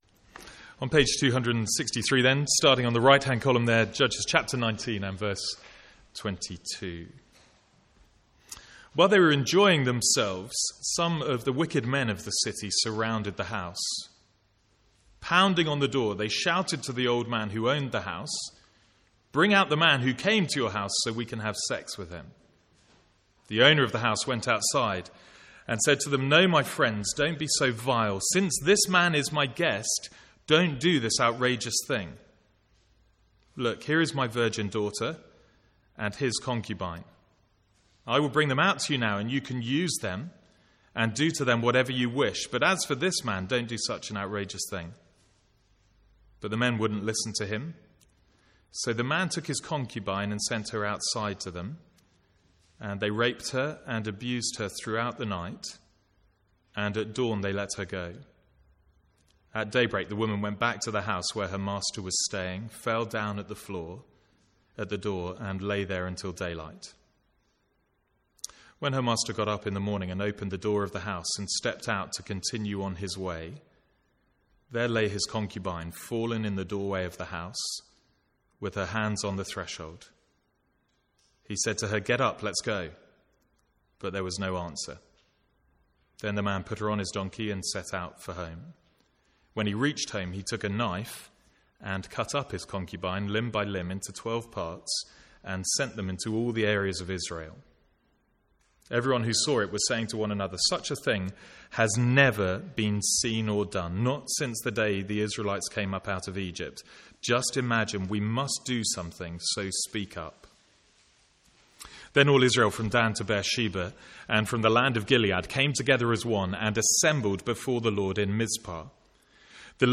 Sermons | St Andrews Free Church
From the Sunday morning series in Judges.